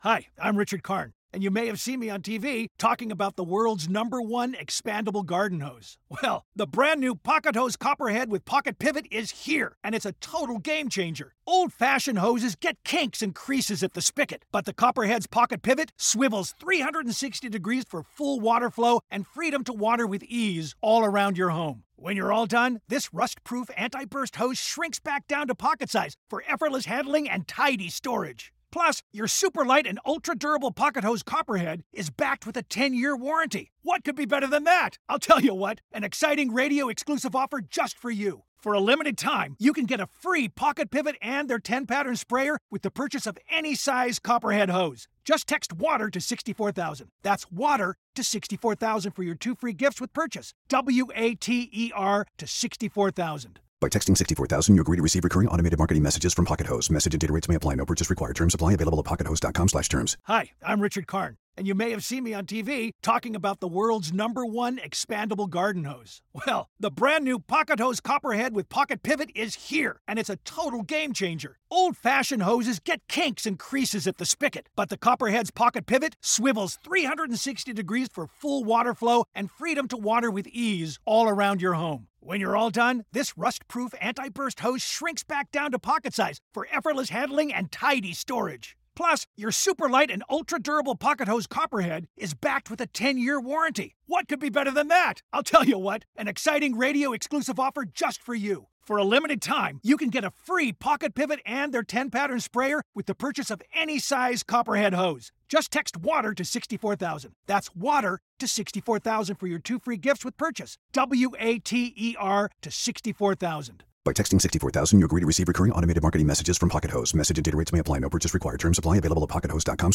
We dish with Hugh Jackman, Zac Efron and Zendaya about their new movie 'The Greatest Showman,' plus a look at 'Pitch Perfect 3,' should you ticket or skip it? Plus all the latest on Eva Longoria, Magic Johnson, Lady Gaga, Margot Robbie and more.